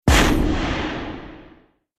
دانلود آهنگ آتش 2 از افکت صوتی طبیعت و محیط
دانلود صدای آتش 2 از ساعد نیوز با لینک مستقیم و کیفیت بالا
جلوه های صوتی